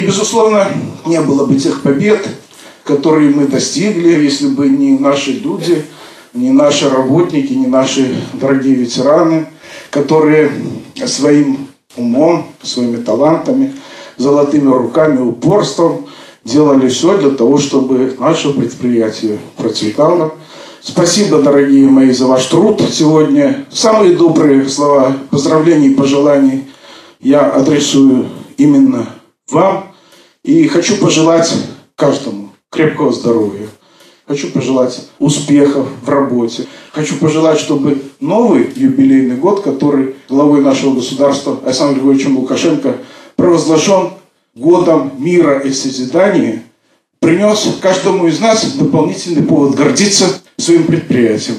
Торжественное мероприятие «Ценить человека. Гордиться предприятием. Любить Родину» состоялось 10 февраля.